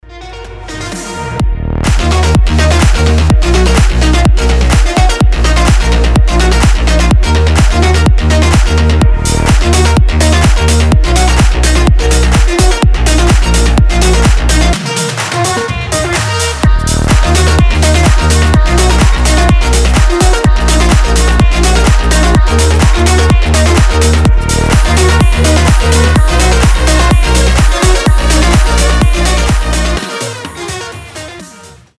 • Качество: 320, Stereo
громкие
мощные
Electronic
EDM
басы
энергичные
Стиль: future house